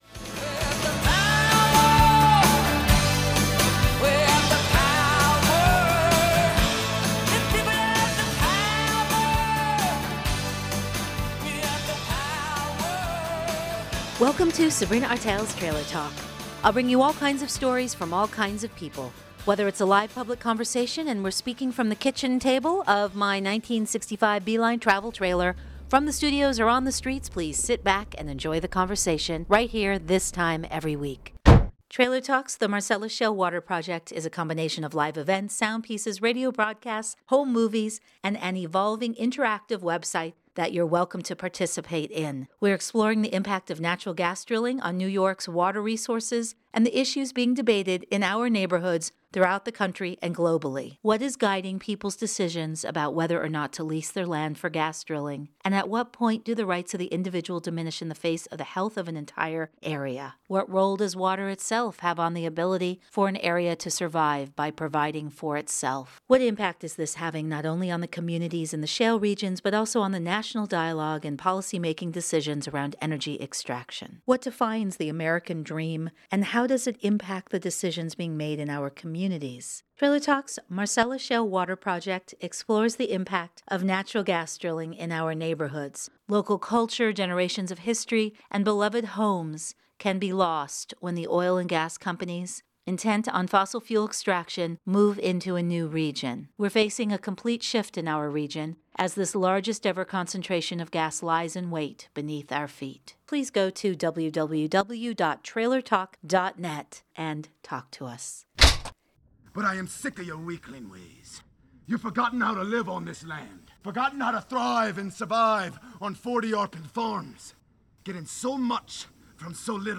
The performance combined the specifics of the farm, with set pieces being excavated from the pasture, the planned sound score of the incredible musicians with the squawking of birds, the bahs of sheep (sharing the pasture with us), the chirping of crickets and the summer breeze blowing through the trees.